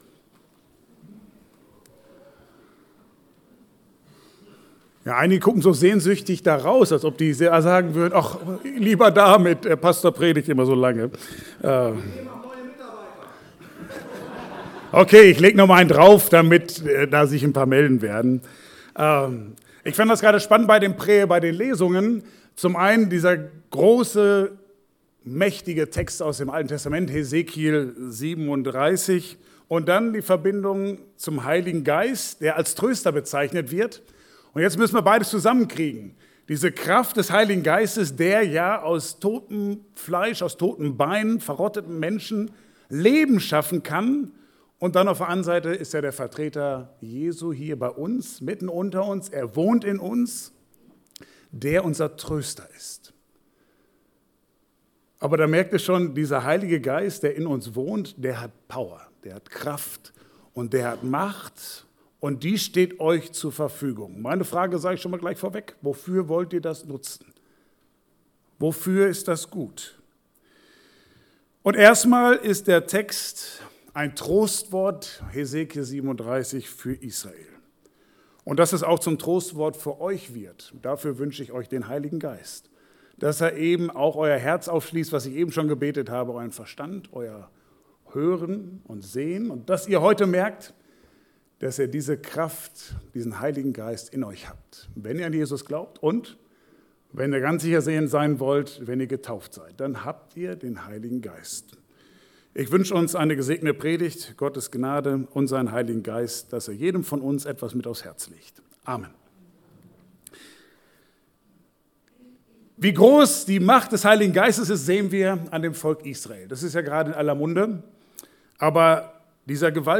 Bibelstelle: Hesekiel 37, 1-14 Dienstart: Gottesdienst « Einer geht